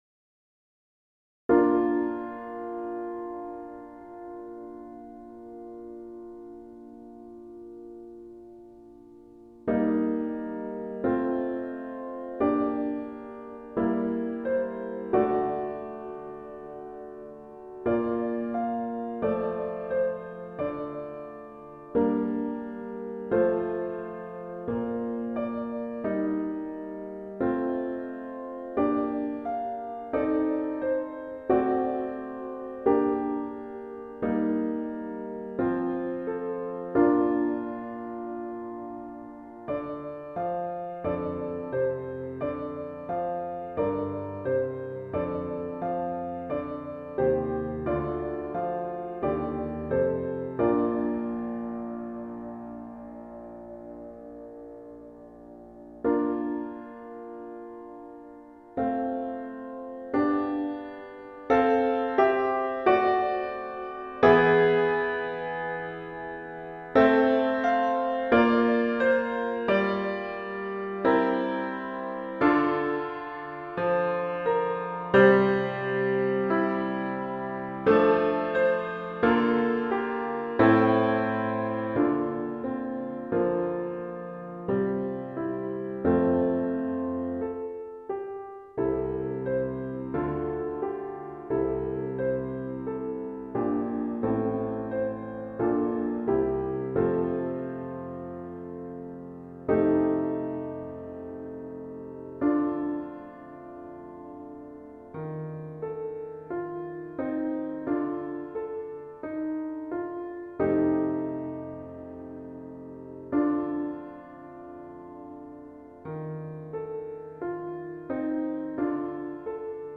Index of /Rehearsal_Tracks/Faure_Requiem/Full Choir Access
Pie Jesu (Requiem) - Accompaniment Only - Gabriel Faure, ed. John Rutter.mp3